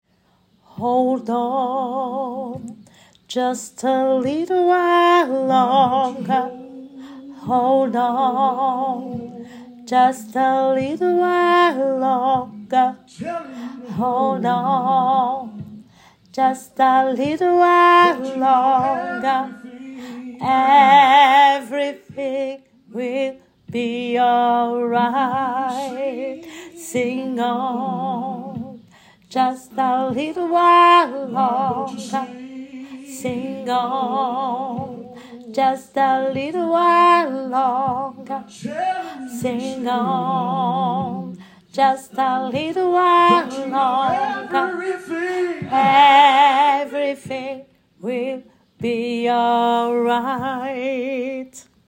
Mezz
Hold-on-MEZZO.mp3